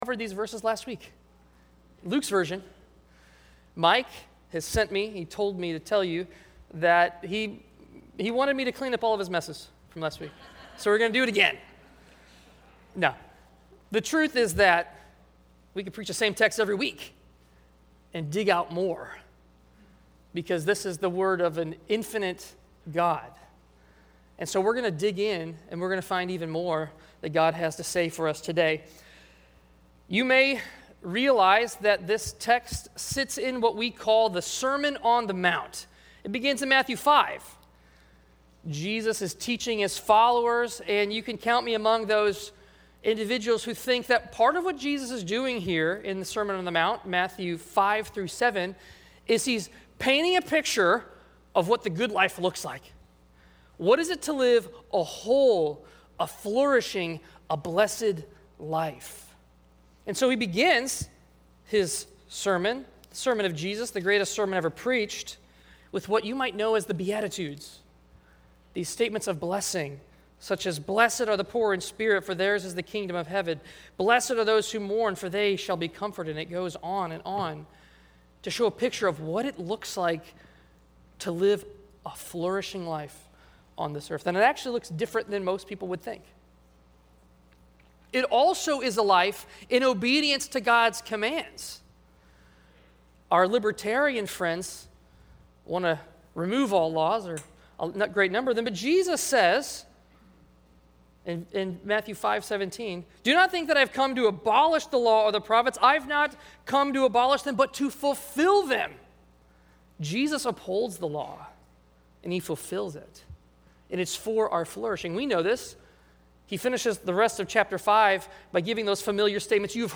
A message from the series "Lord Teach Us To Pray."